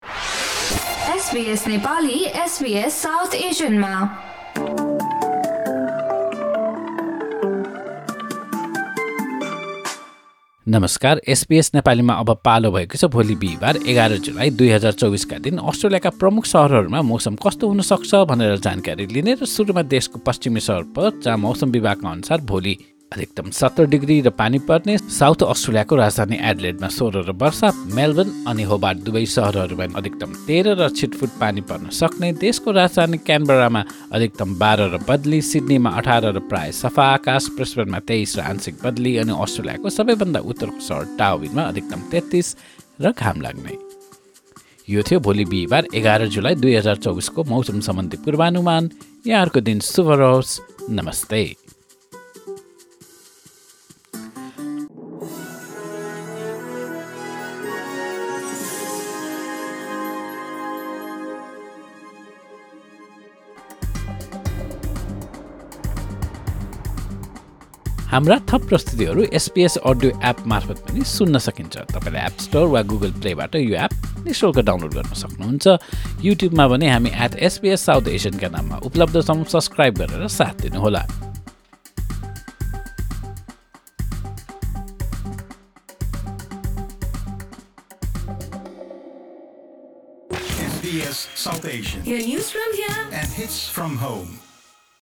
A quick Australian weather update in Nepali language.